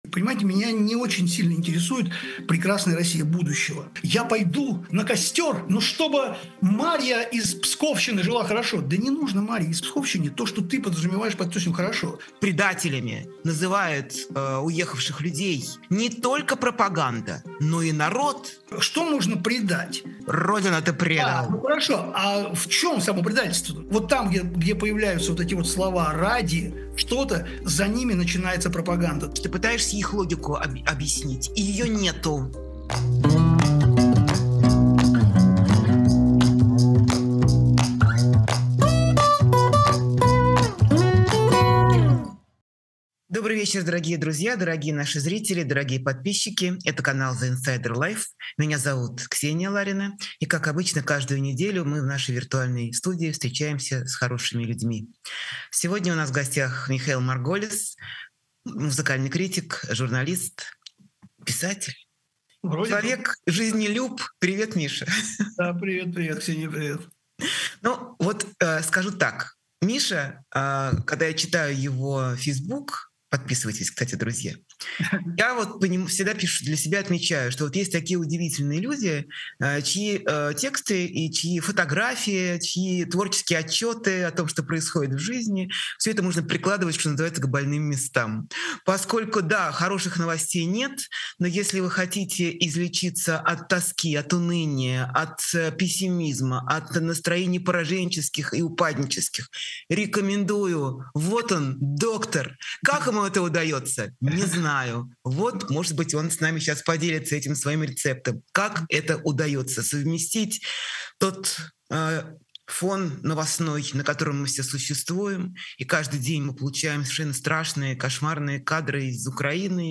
Эфир ведёт Ксения Ларина